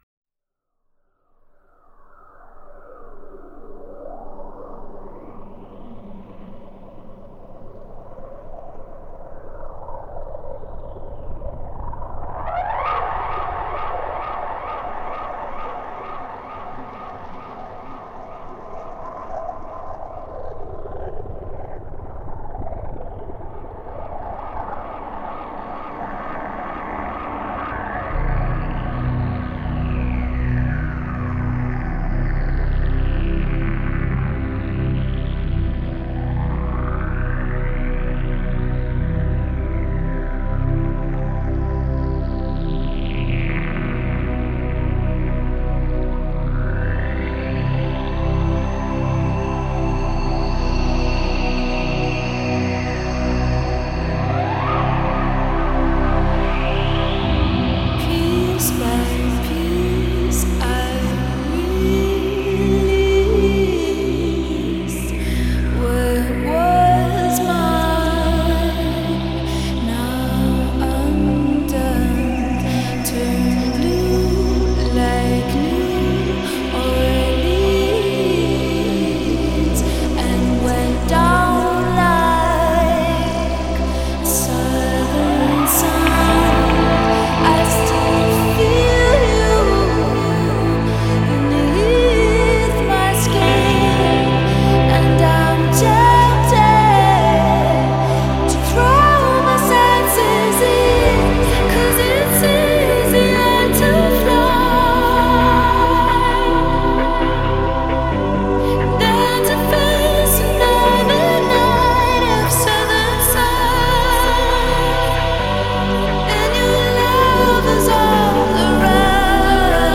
Качество получше.